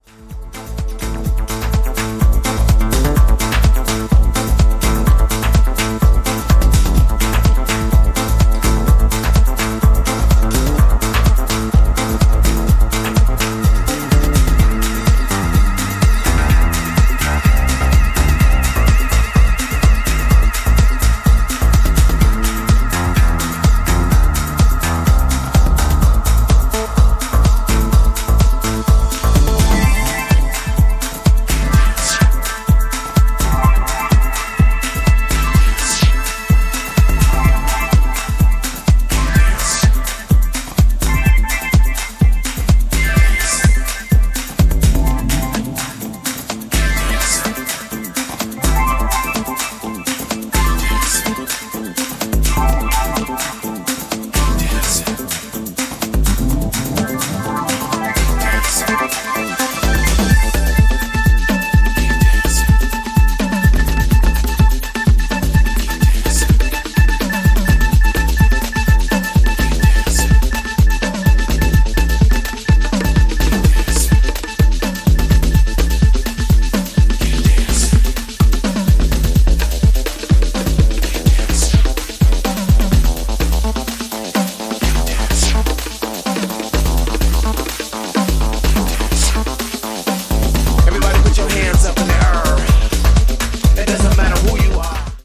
ジャンル(スタイル) DEEP HOUSE / DETROIT